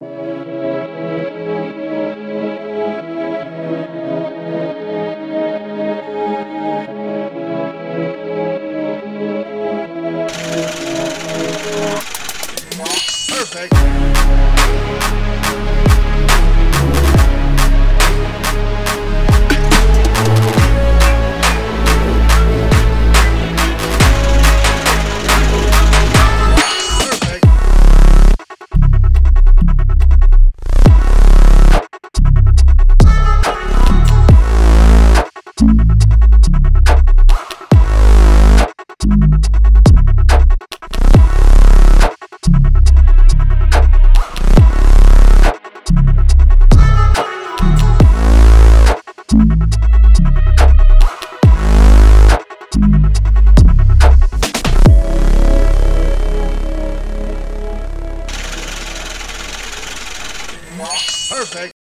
This week, it was the Branché Pack—a Jersey Drill sound pack that’s been turning heads.